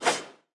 Media:BattleHealer_base_atk_1.wav 攻击音效 atk 初级和经典及以上形态攻击音效